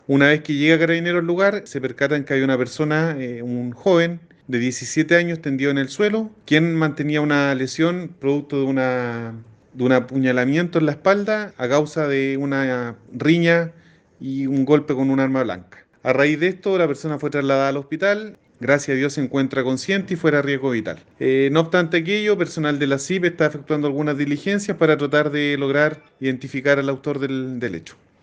carabinero.mp3